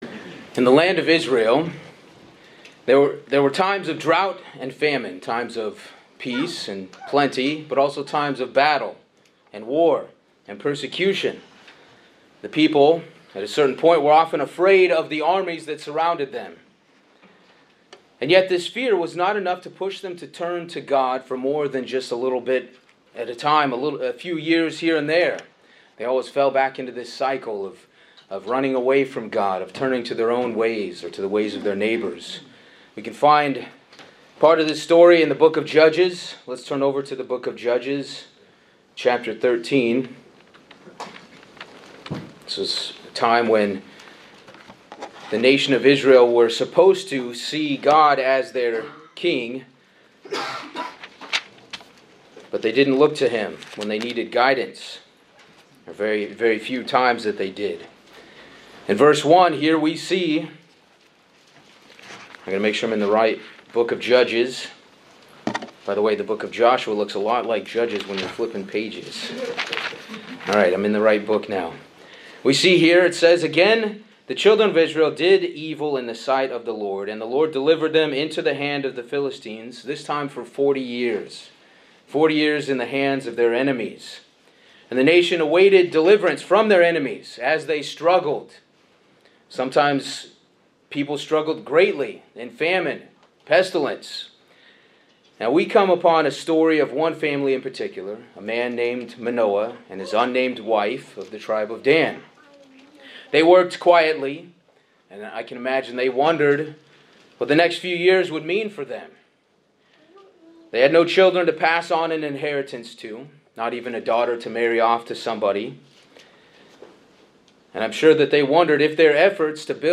Sermons
Given in Hartford, CT